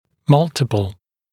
[‘mʌltɪpl][‘малтипл]множественный, многократный, многочисленный